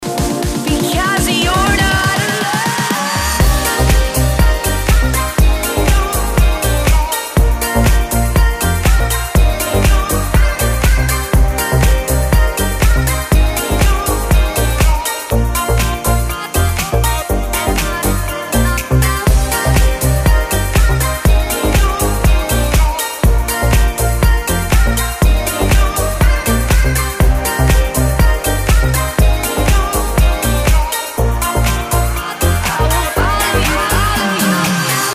• Качество: 320, Stereo
deep house
веселые